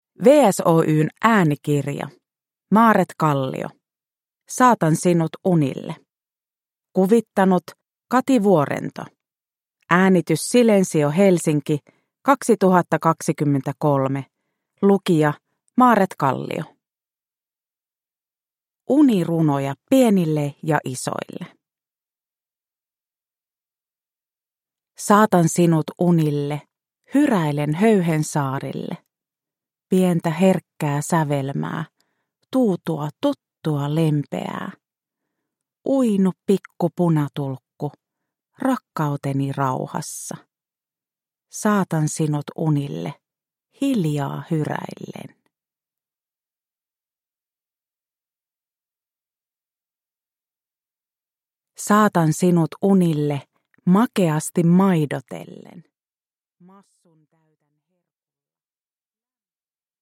Saatan sinut unille – Ljudbok – Laddas ner